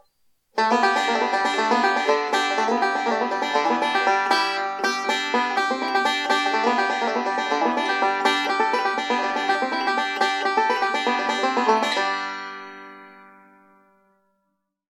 Oscar Schmidt OB5SP-A Banjo - $350 + $75 S/H (US only)
And the tone you get from this banjo is really amazing!